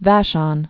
(văshŏn)